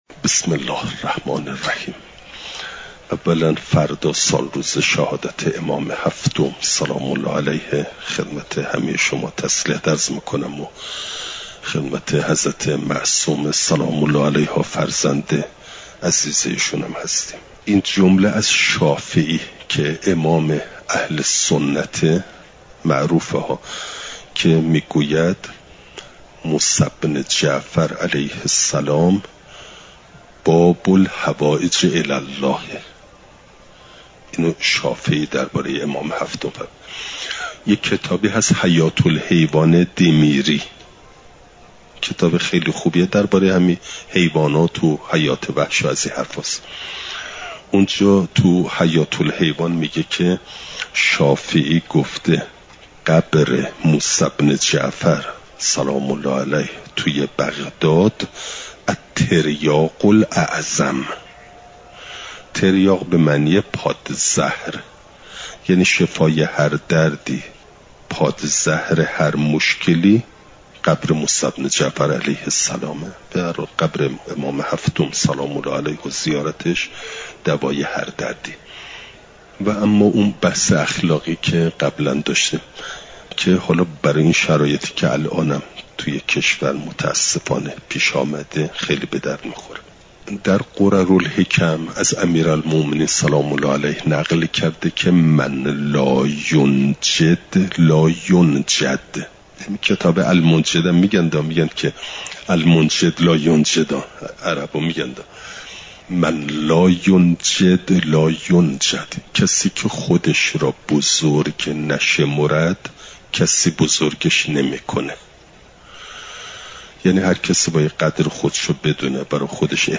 چهارشنبه ۲۴ دیماه ۱۴۰۴، حرم مطهر حضرت معصومه سلام ﷲ علیها